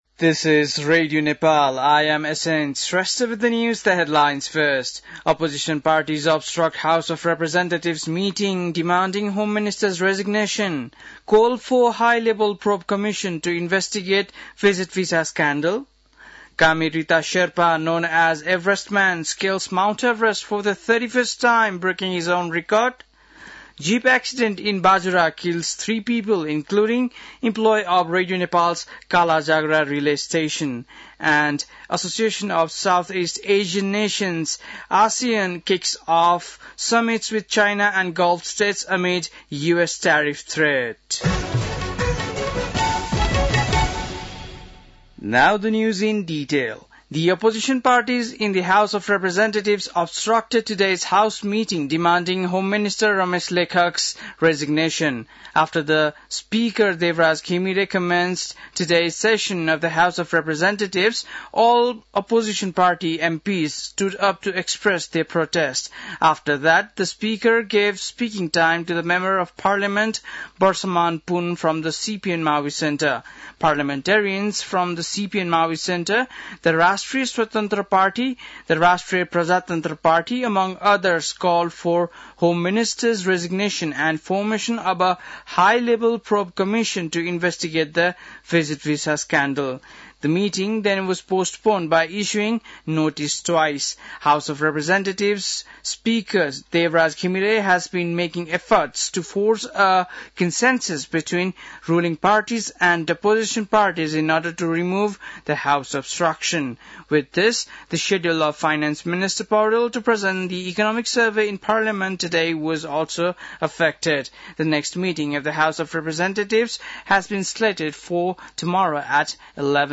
बेलुकी ८ बजेको अङ्ग्रेजी समाचार : १३ जेठ , २०८२